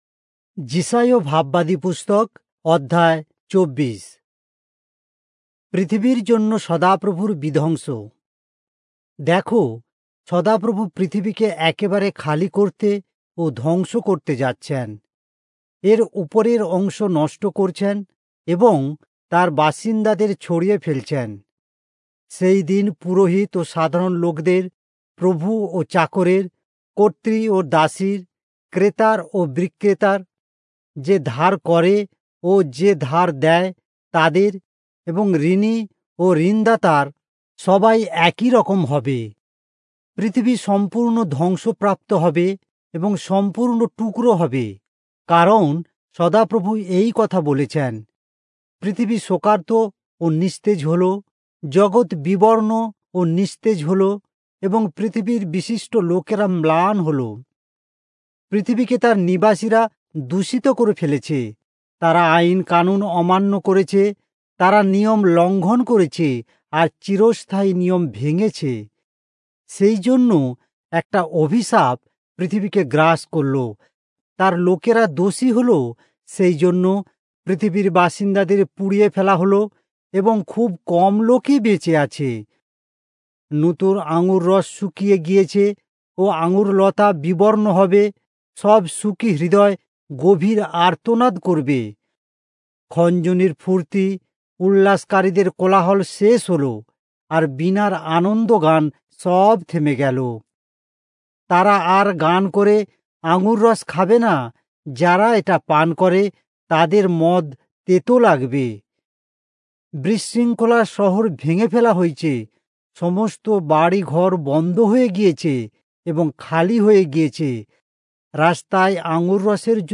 Bengali Audio Bible - Isaiah 32 in Irvbn bible version